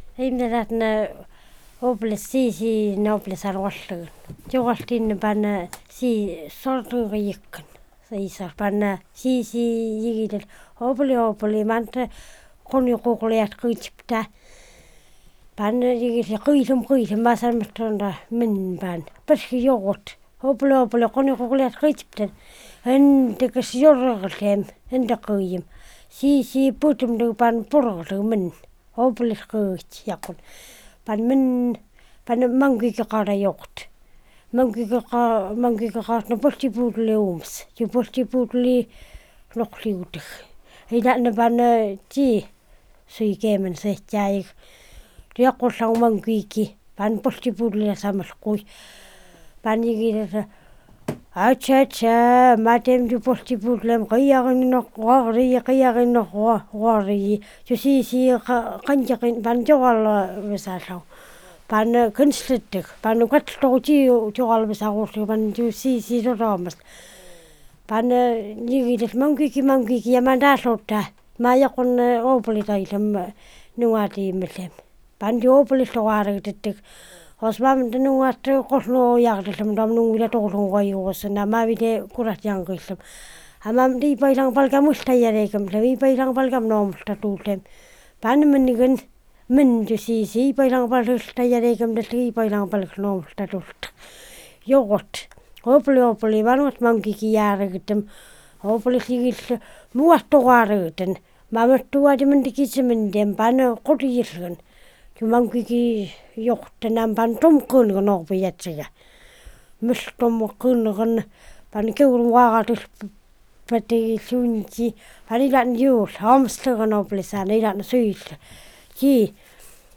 Tales (tal)